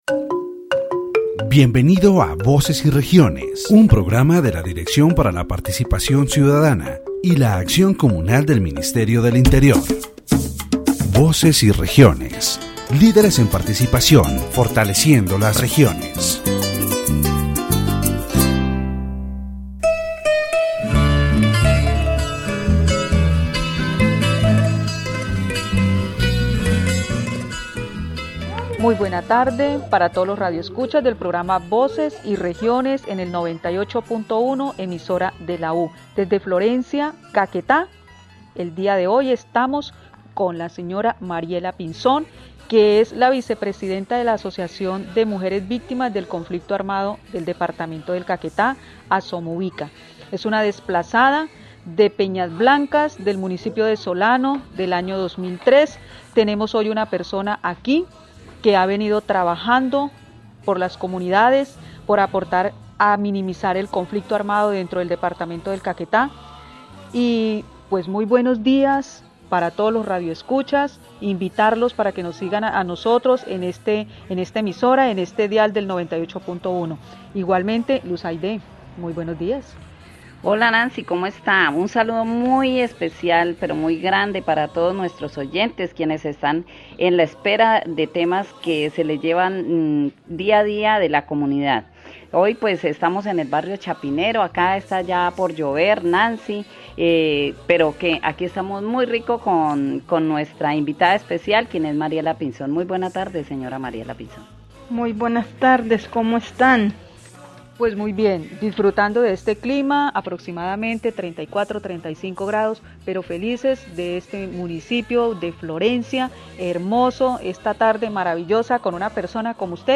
The radio program "Voices and Regions" of the Ministry of the Interior is broadcast from Florencia, Caquetá, on station 98.1. In this episode, the issue of forced displacement and violence in the department of Caquetá is addressed.